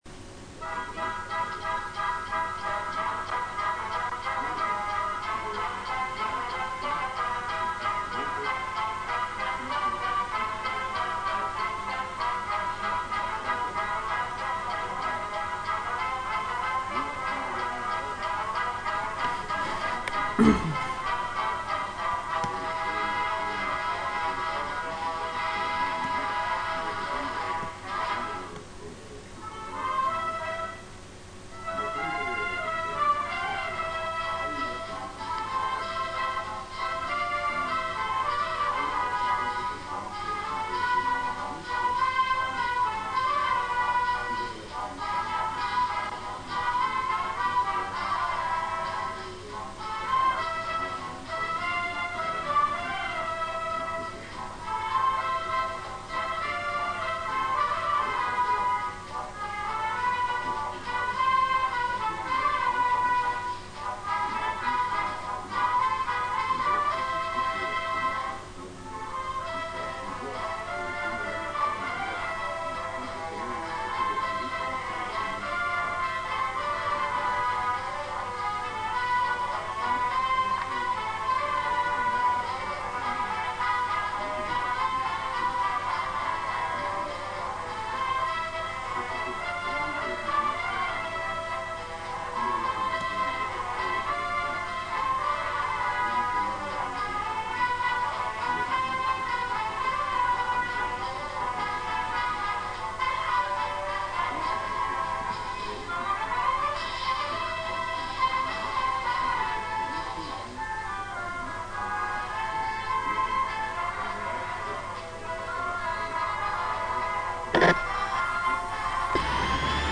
Pasodoble
Dúrcal Paso doble, "DURCAL, PERLA DEL VALLE" (sonido extraido de una grabacion, baja calidad), Autor: Manuel Garin Borrego